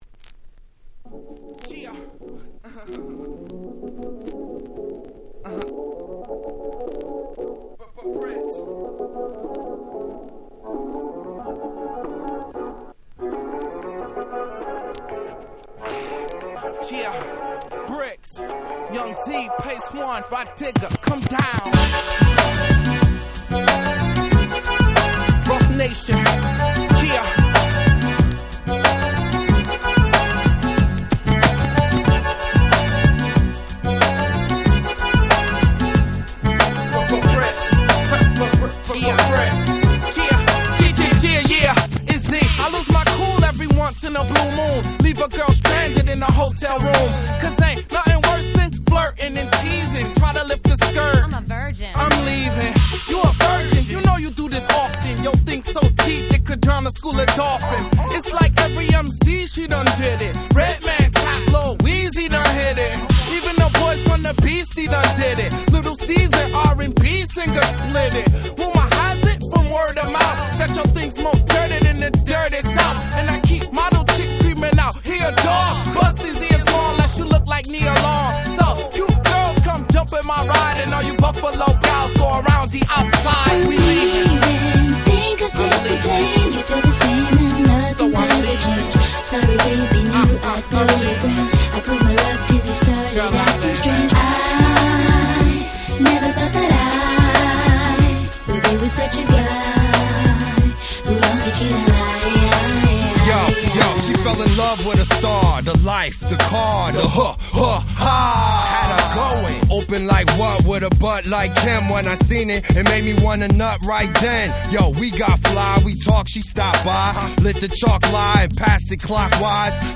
02' Smash Hit Hip Hop !!
日本人受けバッチリな哀愁Beatにサビには女性Vocalも入ってくるキャッチーな1曲！！